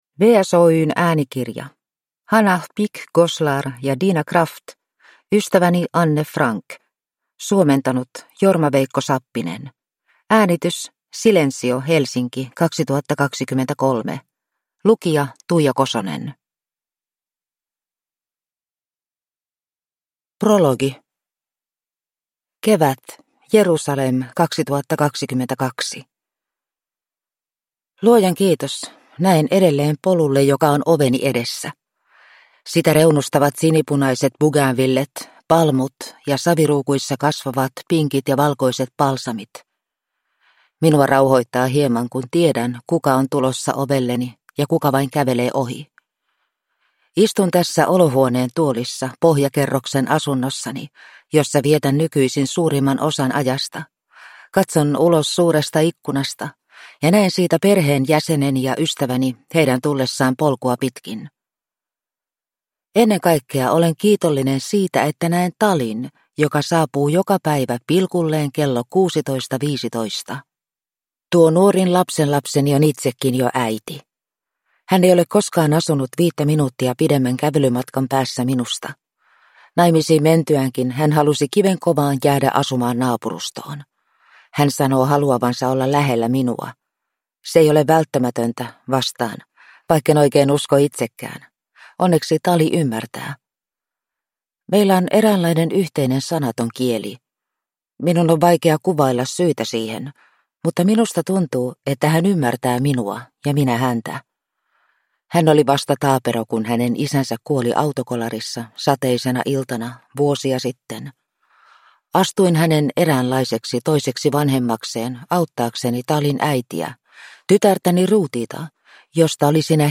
Ystäväni Anne Frank – Ljudbok